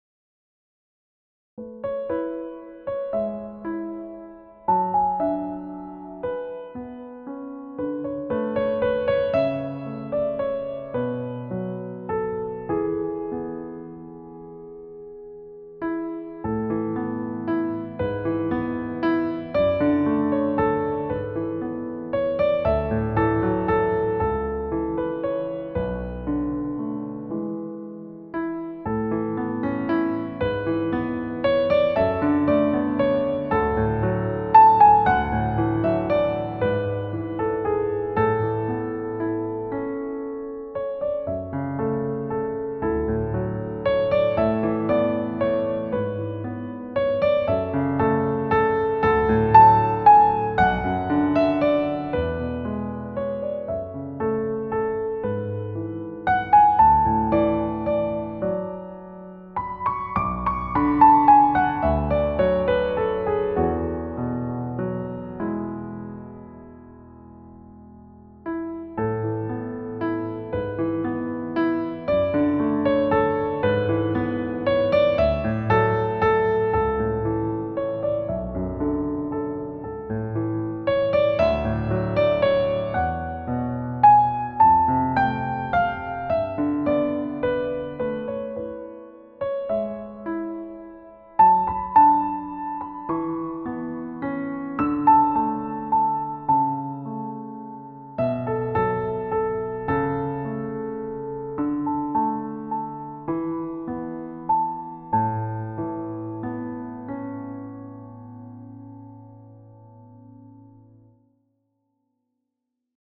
• Key: A Major
• Time signature: 3/4